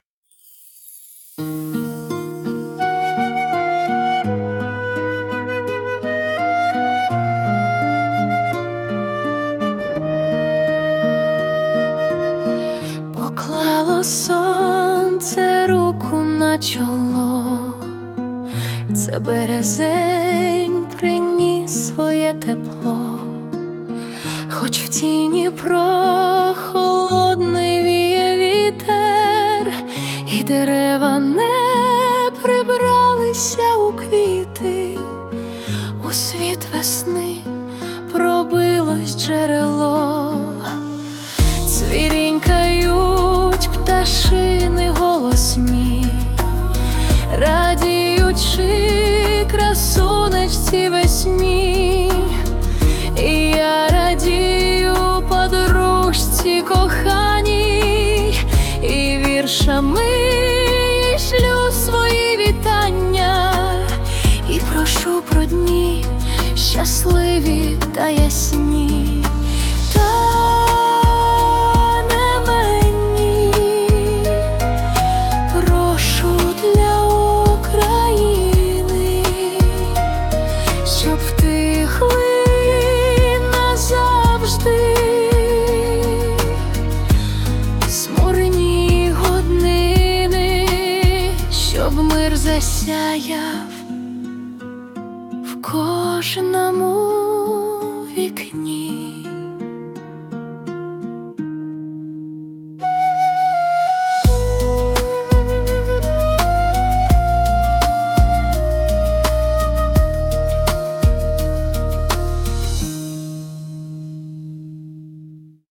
музичний супровід створено ШІ (suno)
СТИЛЬОВІ ЖАНРИ: Ліричний
Наголоси ШІ трохи плутає, але музика та голос теплі.